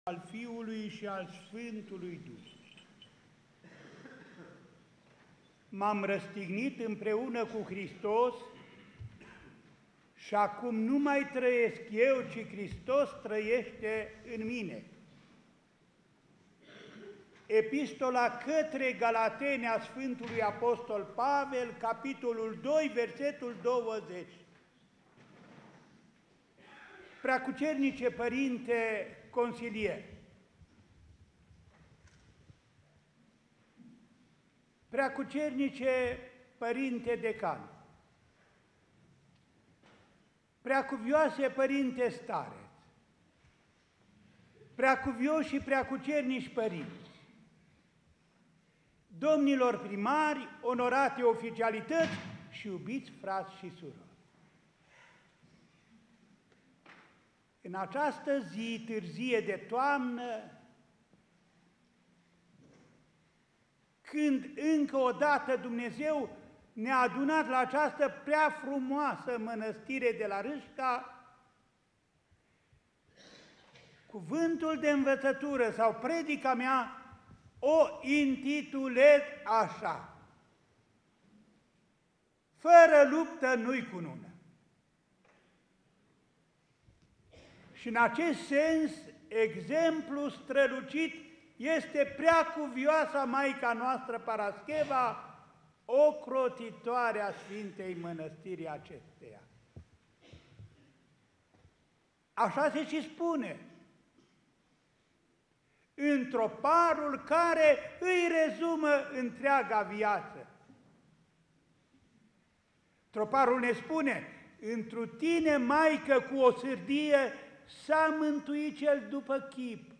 Sfânta a fost sărbătorită în mod deosebit și în Arhiepiscopia Clujului, la Mănăstirea Râșca Transilvană, a cărei biserică o are ca ocrotitoare.
Obștea de călugări alături de o mulțime de credincioși l-au primit pe Înaltpreasfințitul Părinte Andrei, Mitropolitul Clujului vineri, în ziua de prăznuire a Sfintei Parascheva, pentru a le fi alături la sărbătoarea așezământului monahal.
Predica-IPS-Andrei-Fără-luptă-nu-i-cunună.mp3